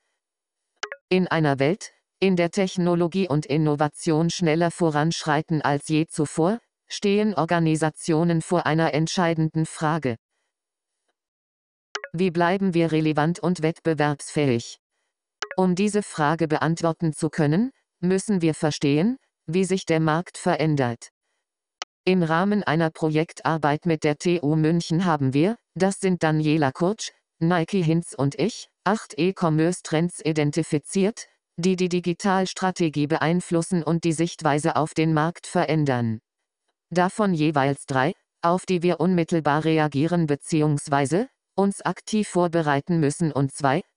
talkback-beispiel.mp3